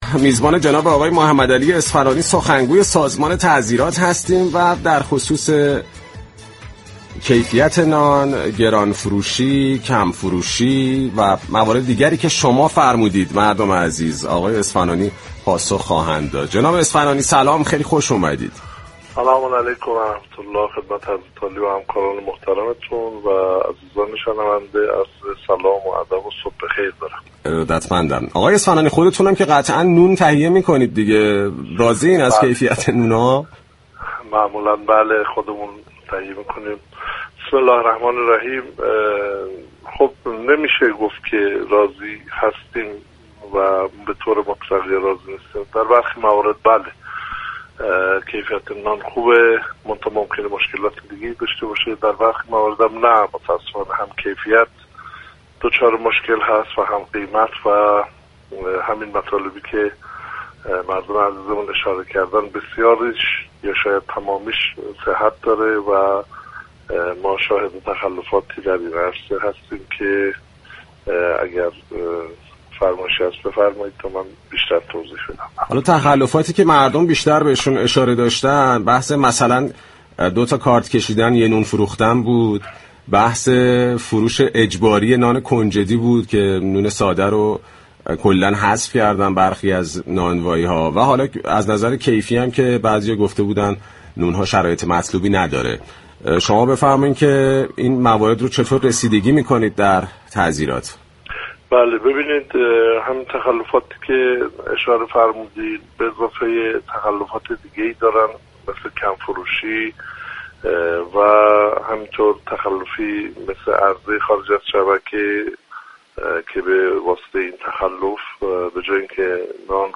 در ارتباط زنده تلفنی با برنامه "صبح نو، تهران نو"